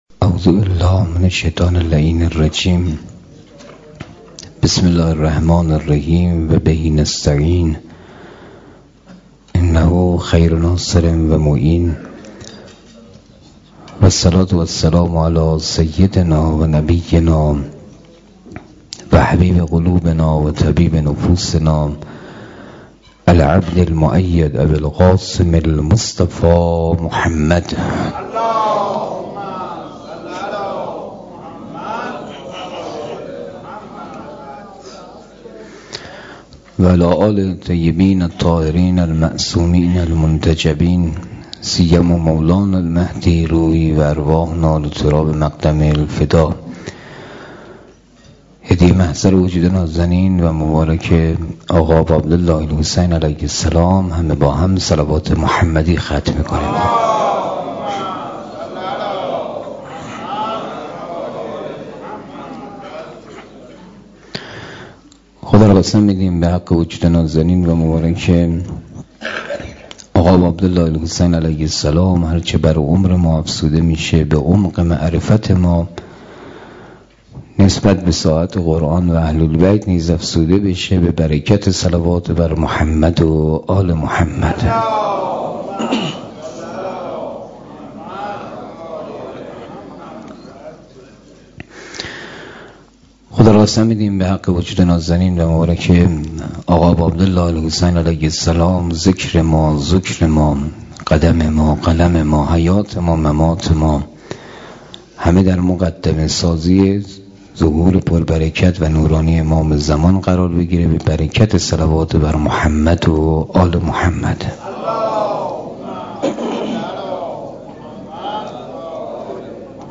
مسجد حضرت خدیجه